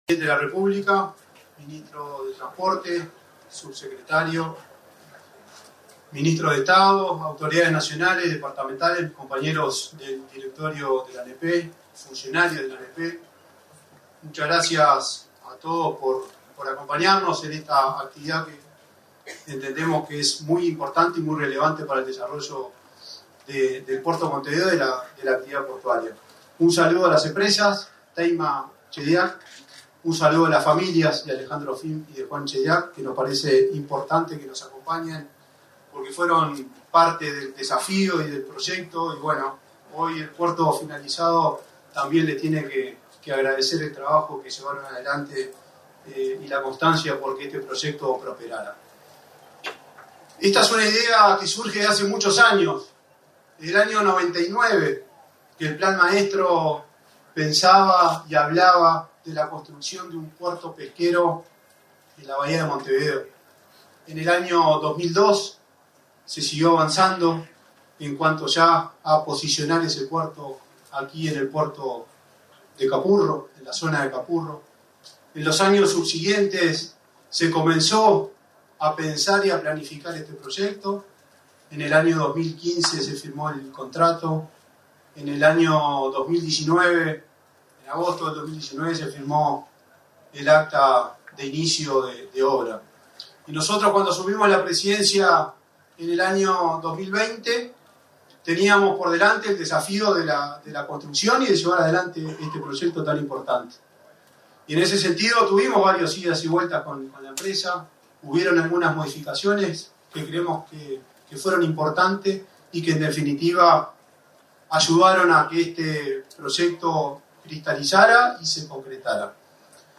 Acto de inauguración del puerto Capurro, en Montevideo
Acto de inauguración del puerto Capurro, en Montevideo 03/09/2024 Compartir Facebook X Copiar enlace WhatsApp LinkedIn Este 3 de setiembre se realizó la ceremonia de inauguración del puerto Capurro, en Montevideo, con la presencia del presidente de la República, Luis Lacalle Pou. En el evento participaron el ministro de Transporte y Obras Públicas, José Luis Falero, y el presidente de la Administración Nacional de Puertos (ANP), Juan Curbelo.